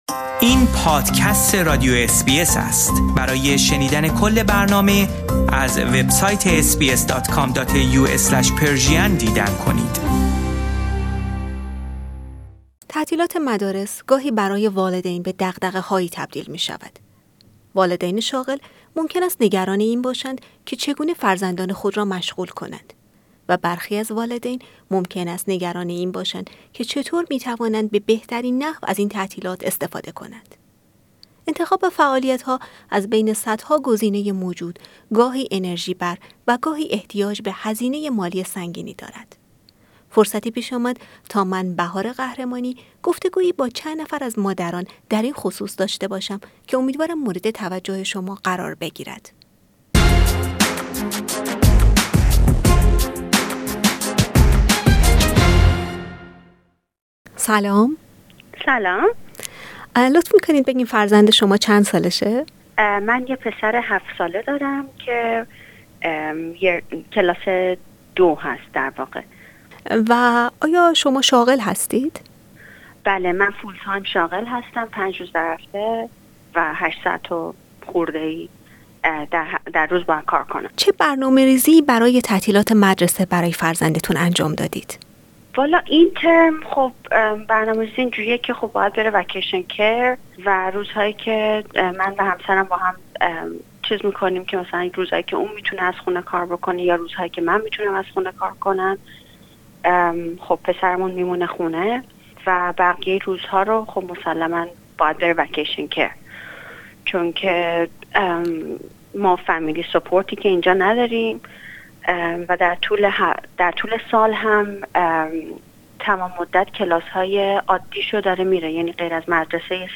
فرصتی پیش آمد تا با چند نفر از والدین در این باره گفتگویی داشته باشیم.